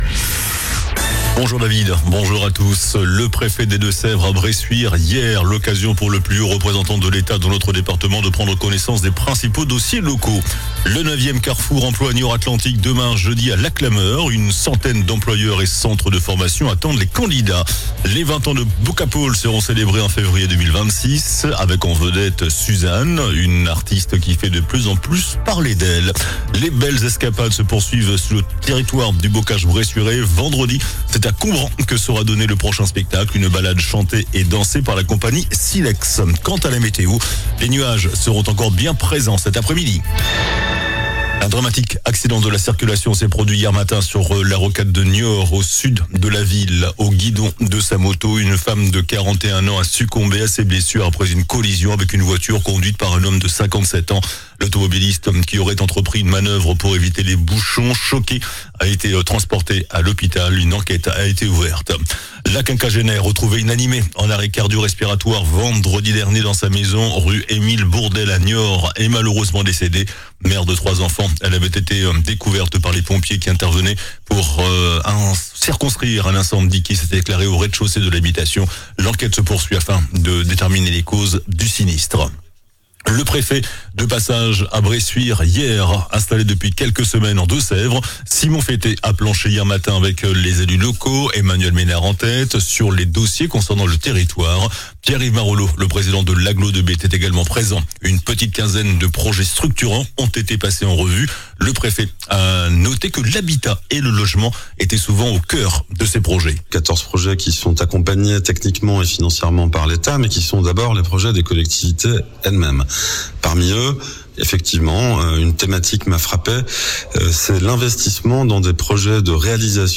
JOURNAL DU MERCREDI 04 JUIN ( MIDI )